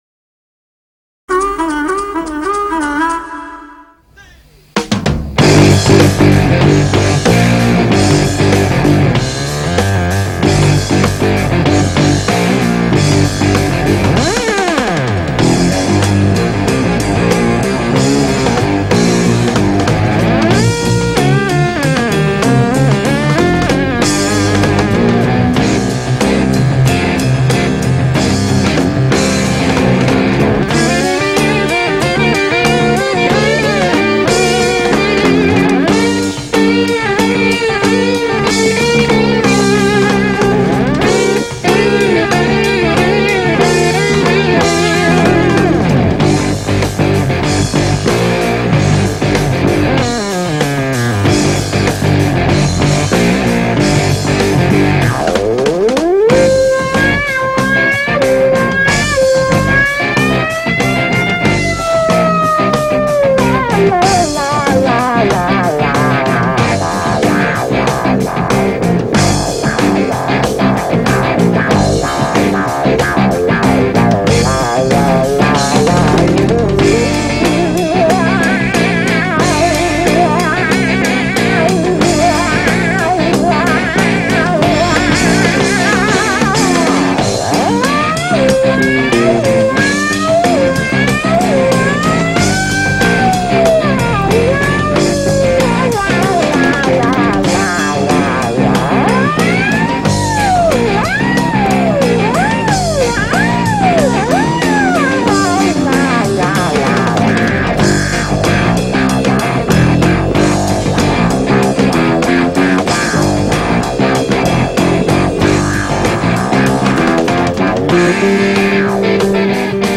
Instrumental rock
Instrumental Funk